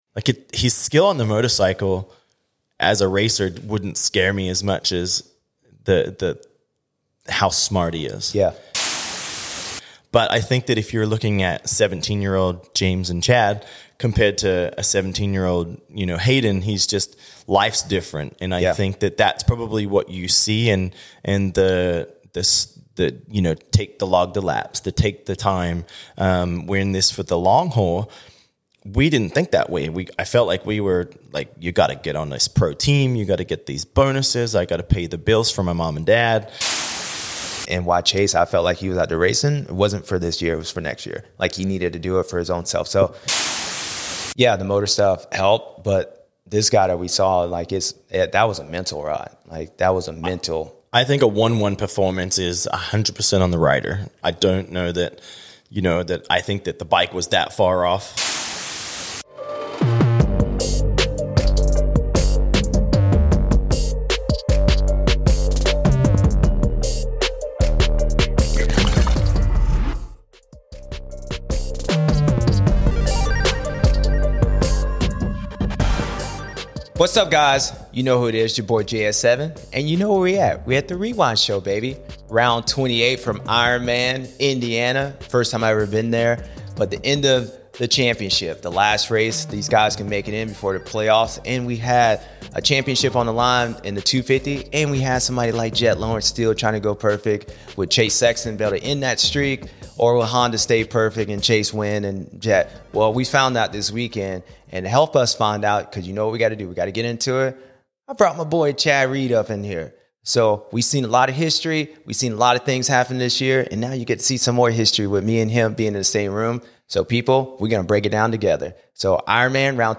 We had none other than Chad Reed come into the studio to break down Ironman and the season as a whole..